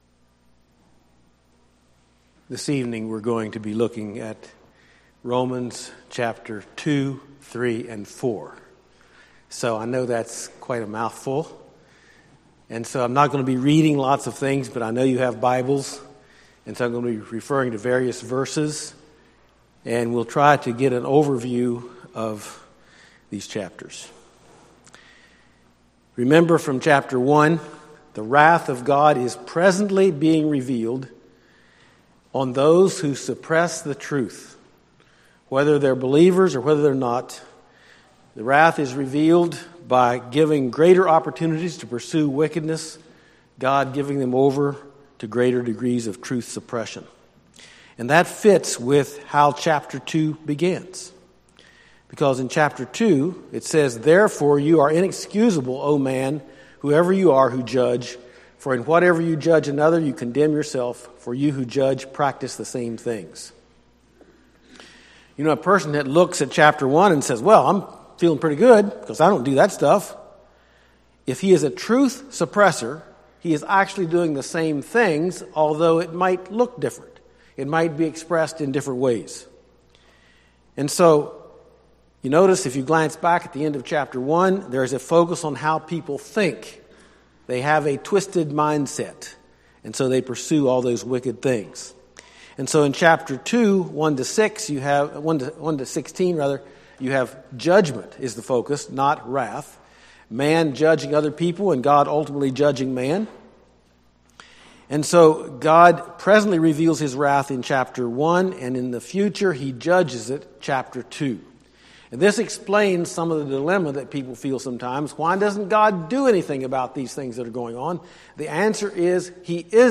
Service Type: Friday Evening